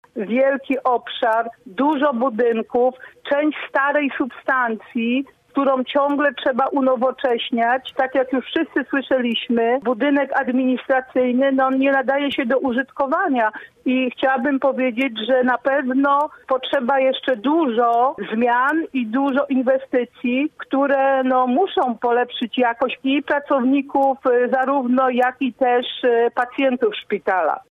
’- Inwestycje w Szpitalu Uniwersyteckim w Zielonej Górze są konieczne – mówiła dziś w „Rozmowie po 9” Anna Chinalska.